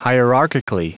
Prononciation du mot hierarchically en anglais (fichier audio)
Prononciation du mot : hierarchically